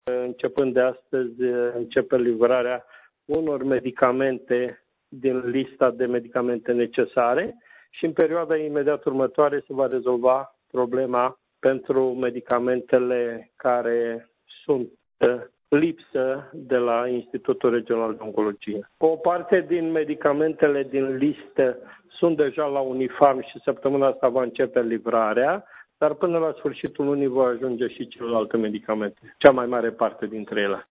Deputatul PMP de Iaşi, Petru Movilă, membru în Comisia de Sănătate, a declarat, pentru postul nostru de radio că în urma unei discuţii cu Ministrul Sănătăţii, Victor Costache, şi cu preşedintele UNIFARM, Adrian Ionel, s-a stabilit că, începând de astăzi, vor fi livrate medicamentele pentru tratarea bolnavilor de cancer care lipsesc din farmacia Institutului de Oncologie din Iaşi.